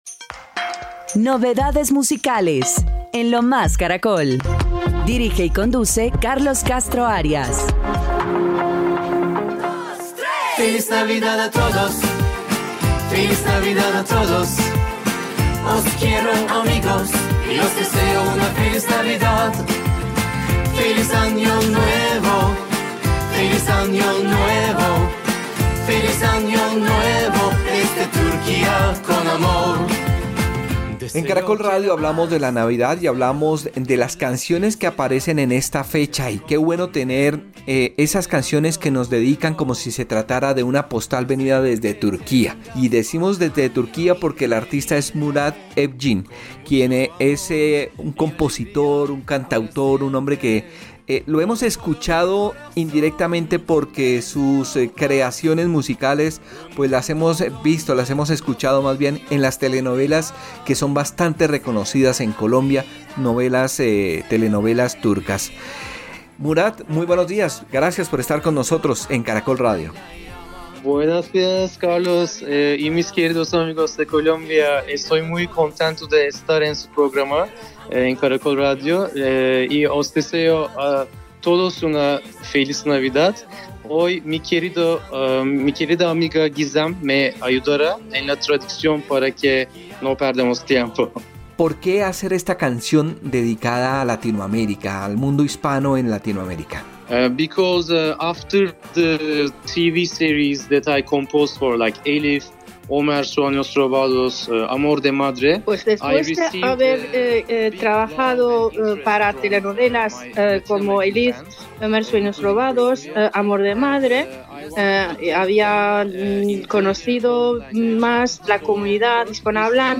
En diálogo con Lo Más Caracol recordó que mientras estivo en Bogotá asistió a un concierto de Juanes pero además conoció a los protagonistas del sector de la industria musical en Colombia.